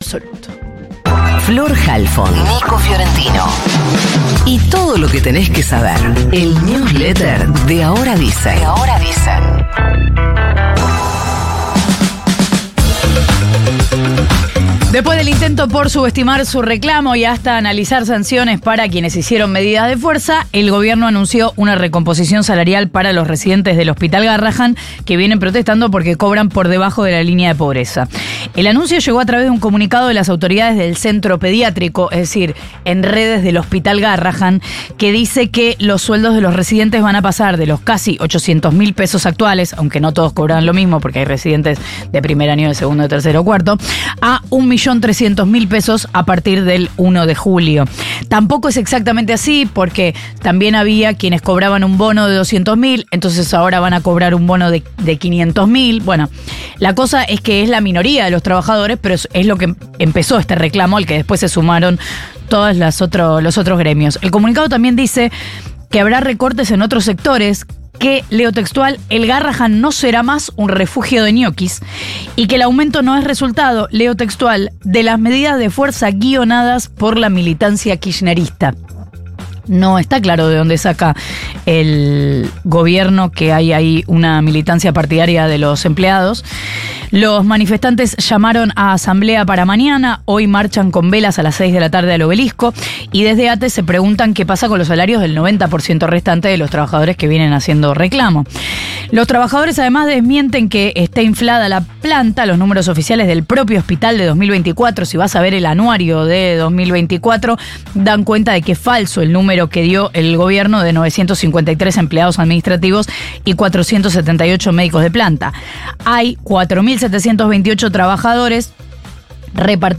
diálogo con el programa “Ahora Dicen” de radio Futurock.